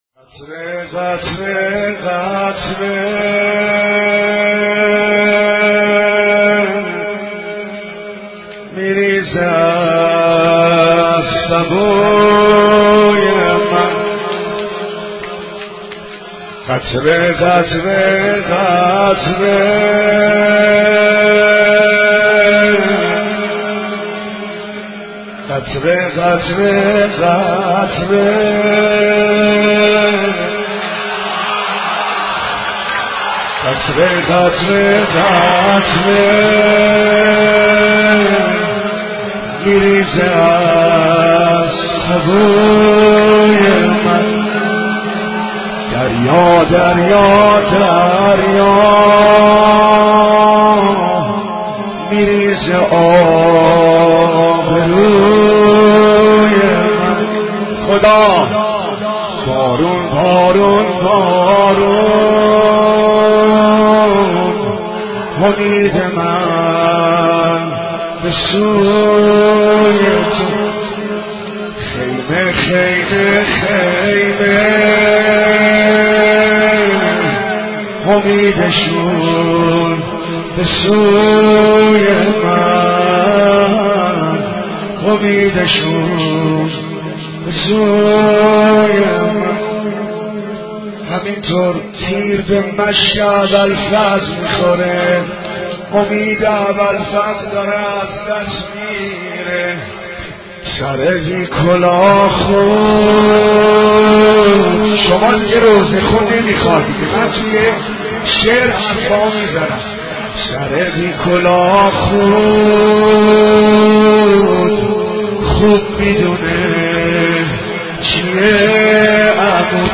نوحه و مداحی مشک پاره سقا
نوحه ، مداحی ، مرثیه و روضه ویژه محرم